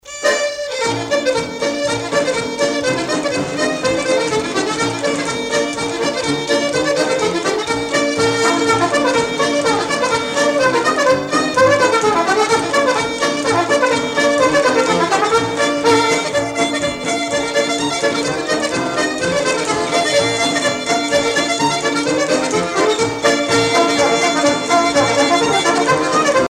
danse : cercle circassien
Pièce musicale éditée